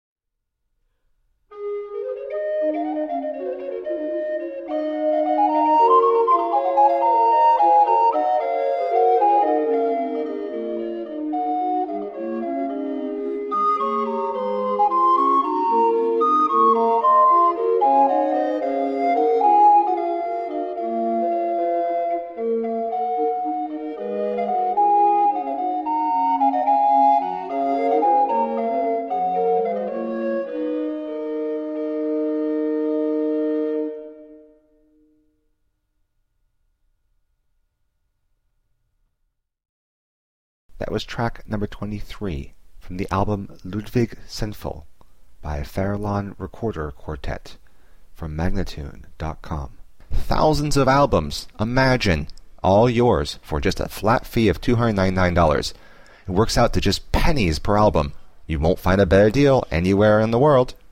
Renaissance recorder ensemble.
Classical, Renaissance, Instrumental, Recorder